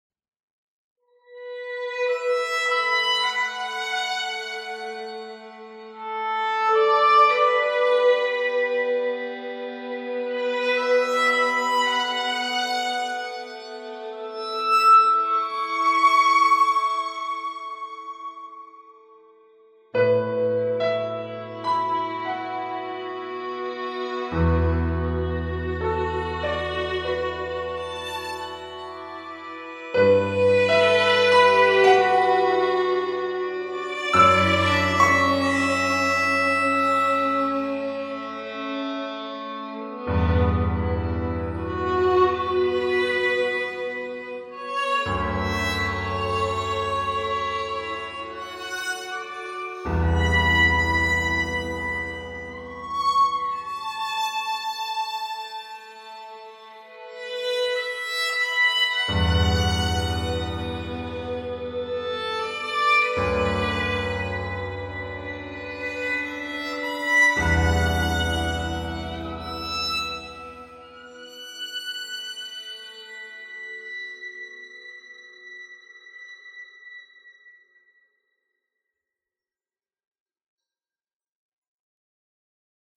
Musicien russe violoniste